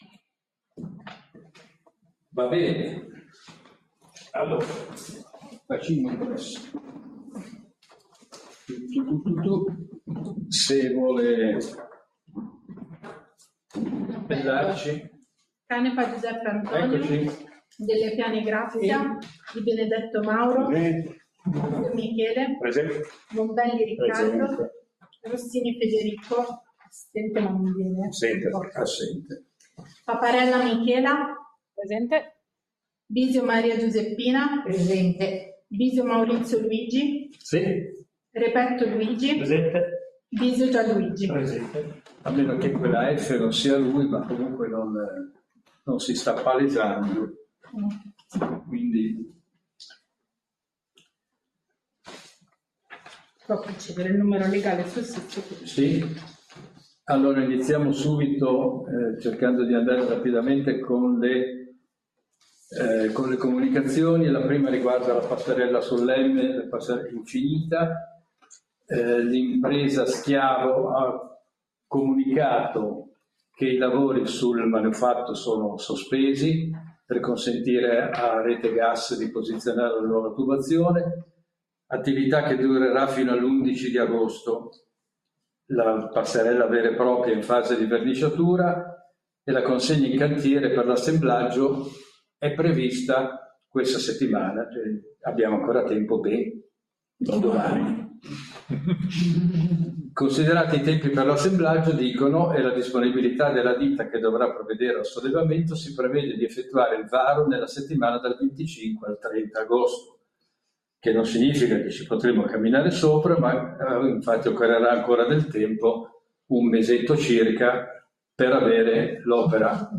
Seduta del Consiglio Comunale del 31/07/2025